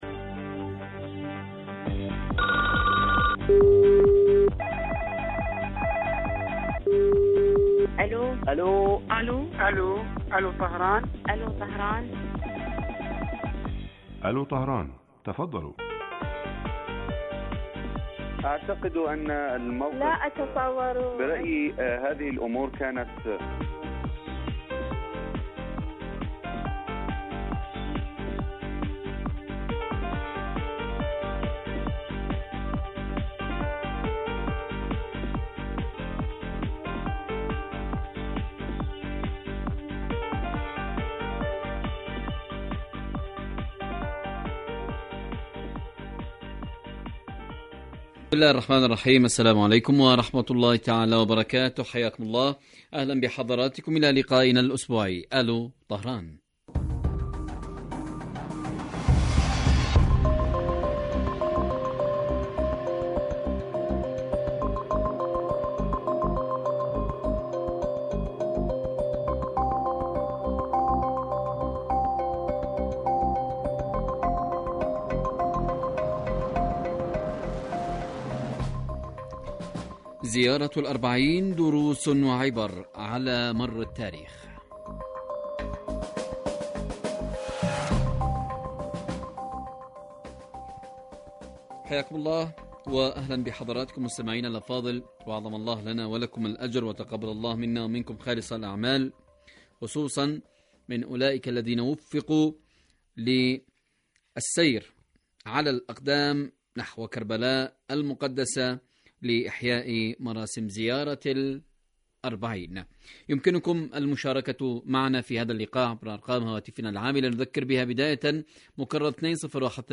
برنامج حي يهدف إلى تنمية الوعي السياسي من خلال طرح إحدى قضايا الساعة الإيرانية والعالمية حيث يتولى مقدم البرنامج دور خبير البرنامج أيضا ويستهل البرنامج بمقدمة يطرح من خلال محور الموضوع على المستمعين لمناقشته عبر مداخلاتهم الهاتفية
يبث هذا البرنامج على الهواء مباشرة مساء أيام الجمعة وعلى مدى ثلاثين دقيقة